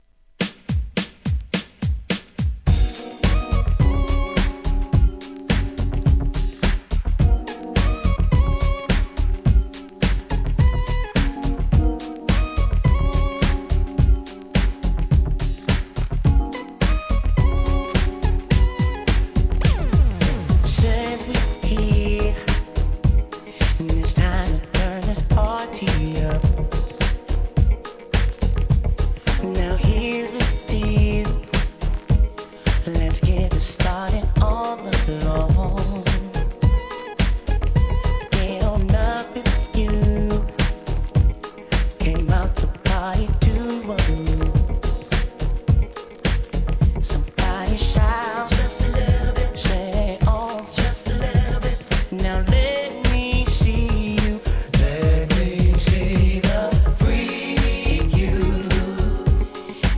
HIP HOP/R&B
スムース・アップR&B!!